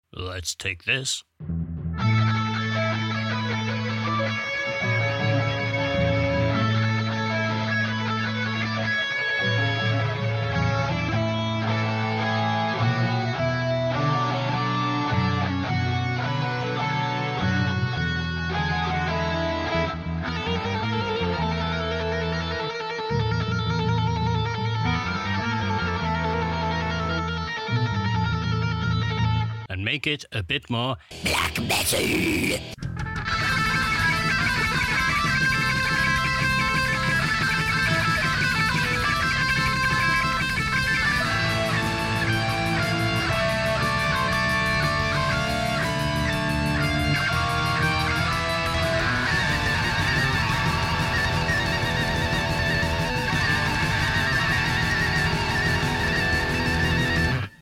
black metal